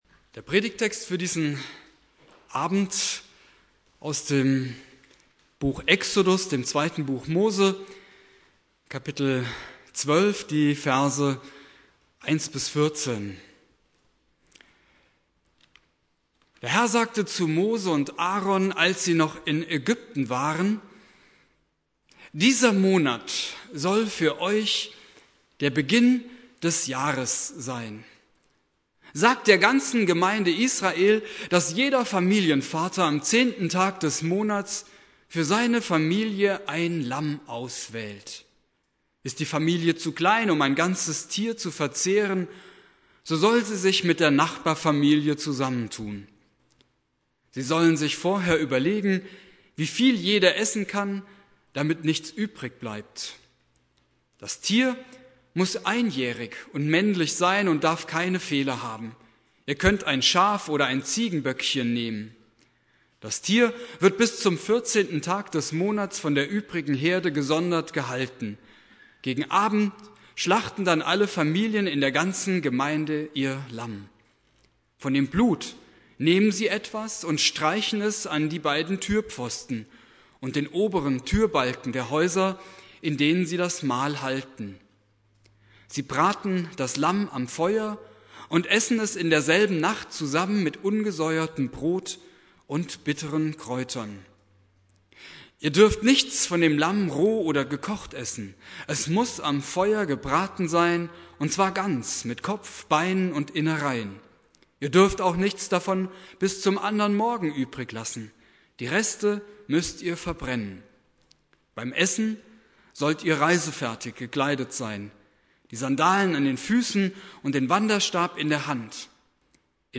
Predigt
Gründonnerstag